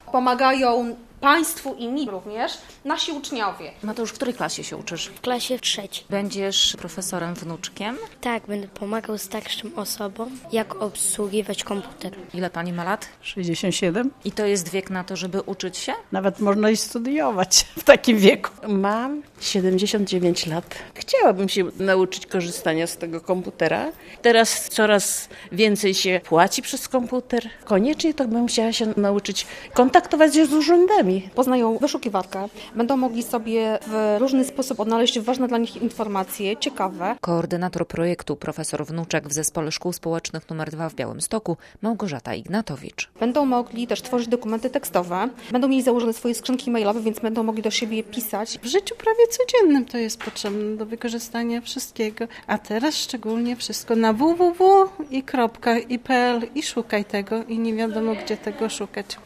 Dzieci uczą dorosłych obsługi komputera - relacja